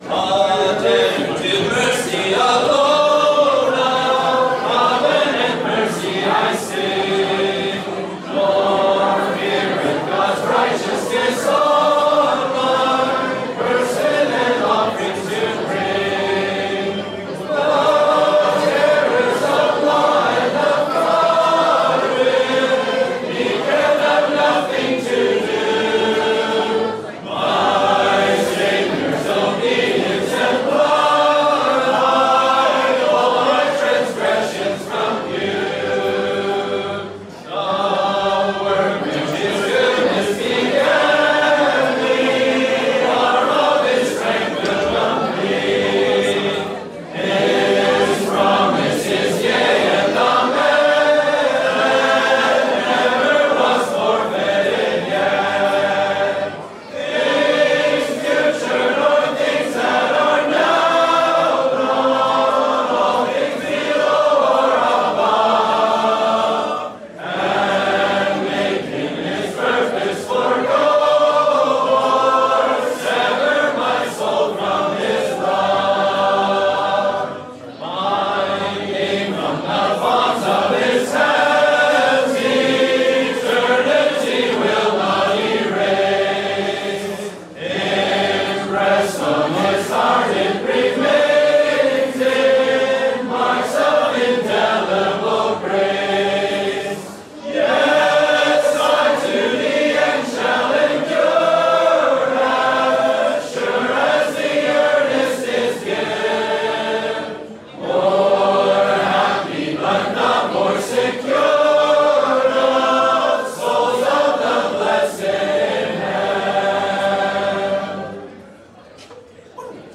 Hymn singing
(Recorded at the Sarnia Gospel Hall Conference, Ontario, Canada, May 2025)